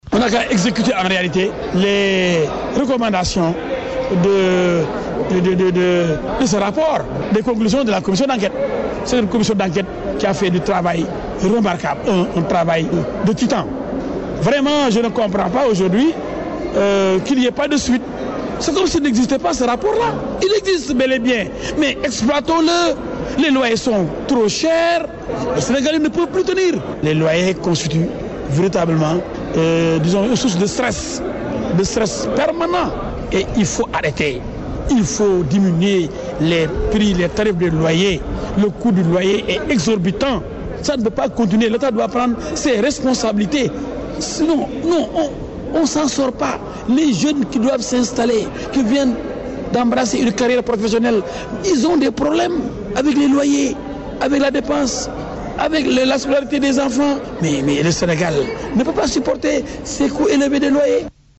Dans la logique, il demande au successeur de ce dernier (Macky Sall) de diminuer le prix des loyers au Sénégal. Me El Hadji est interrogé par nos confrères de la Radio Rfm.